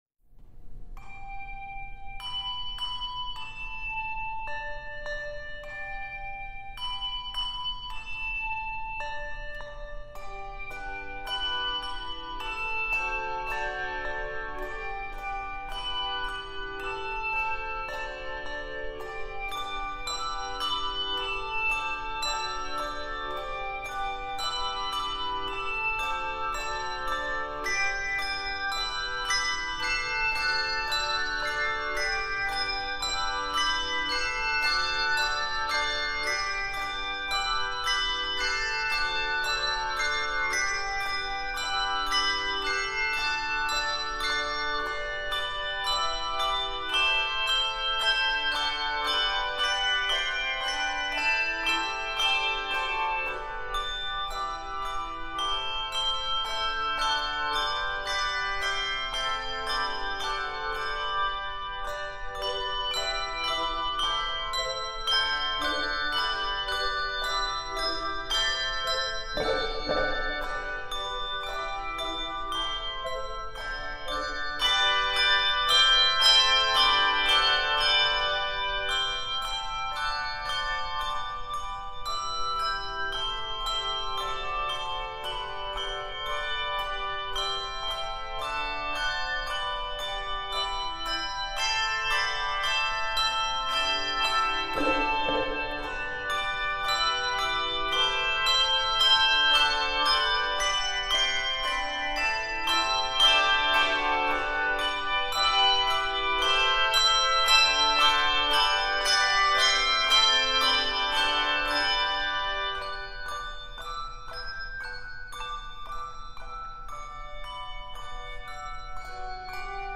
Voicing: Handbells 2-3 Octave